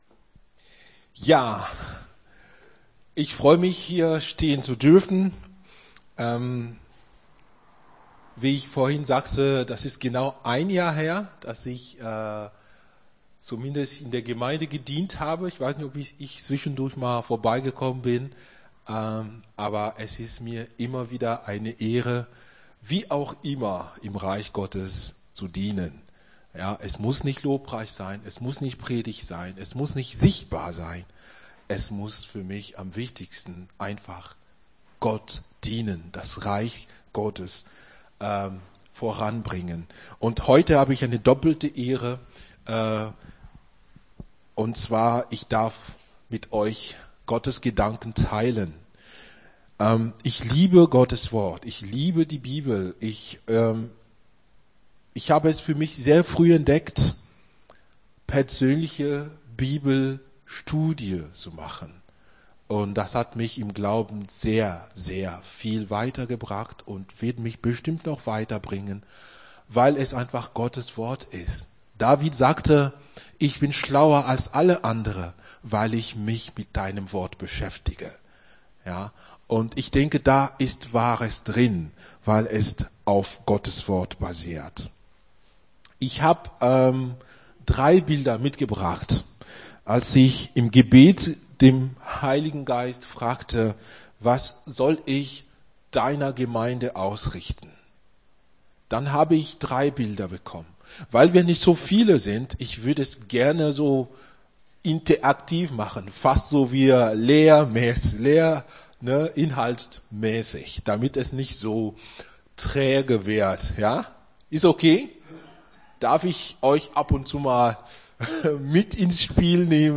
Gottesdienst 25.06.23 - FCG Hagen